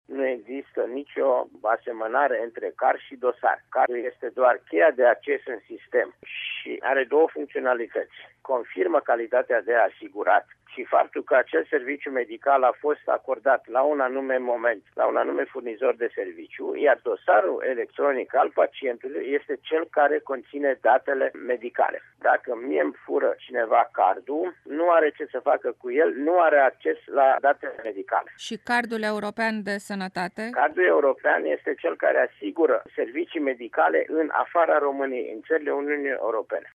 Măsura va fi menţinută timp de încă o lună sau două, a declarat preşedintele Casei Naţionale de Asigurări de Sănătate, Vasile Ciurchea.